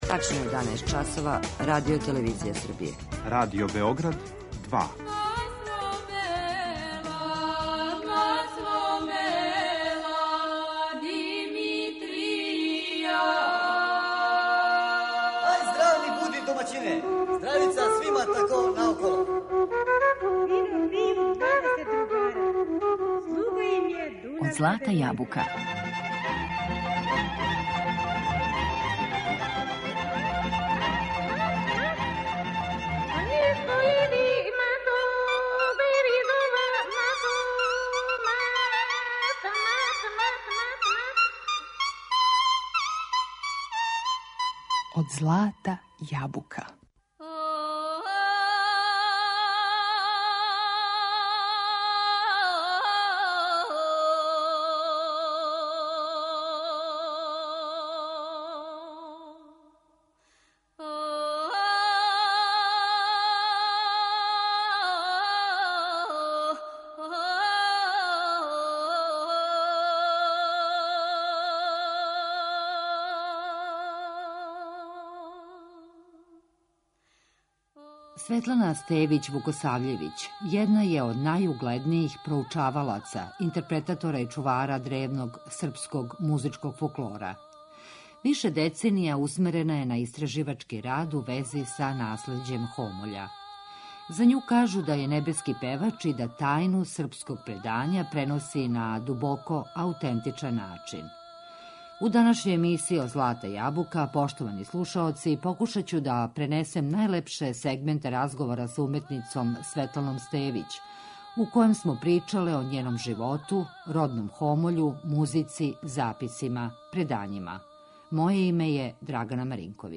За њу кажу да је „небески певач" и да тајну српског предања преноси на дубоко аутентичан начин.